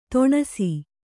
♪ to'ṇasi